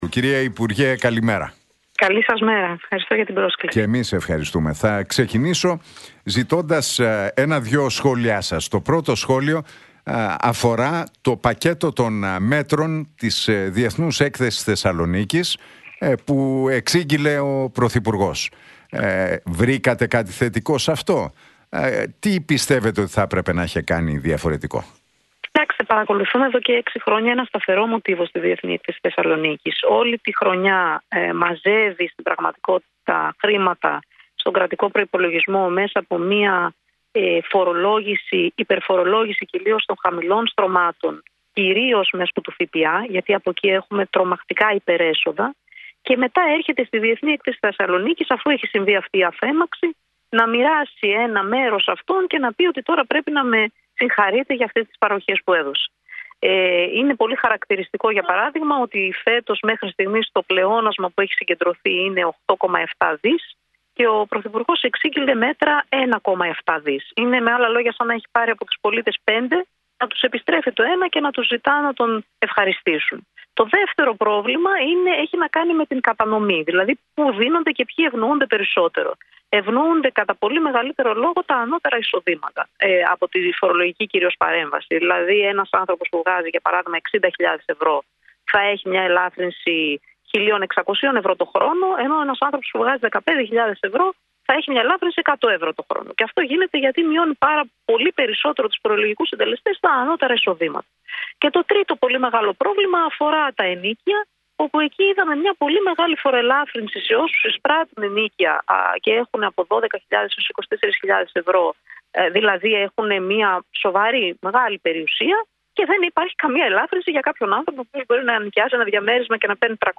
Για τα μέτρα που εξήγγειλε στην ΔΕΘ ο Πρωθυπουργός, το νέο νομοσχέδιο του υπουργείου Εργασίας αλλά και τις εξελίξεις στην αντιπολίτευση μίλησε η βουλευτής της Νέας Αριστεράς, Έφη Αχτσιόγλου στον Νίκο Χατζηνικολάου από την συχνότητα Realfm 97,8.